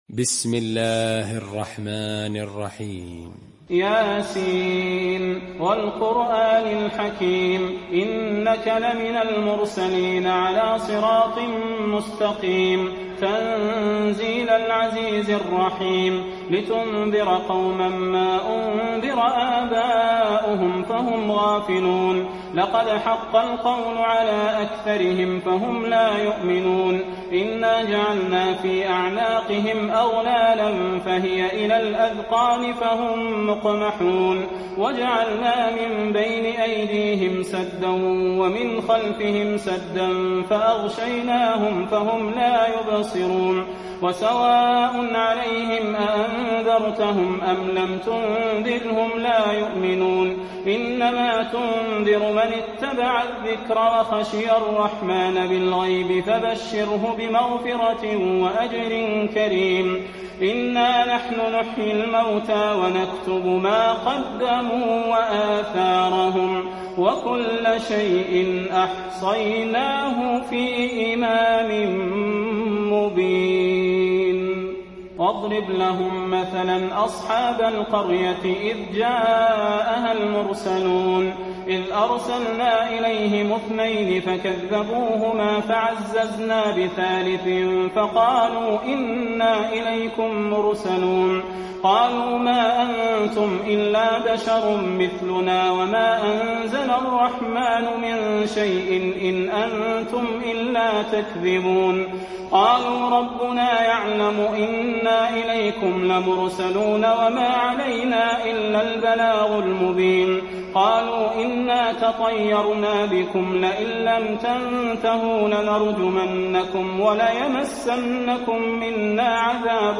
المكان: المسجد النبوي يس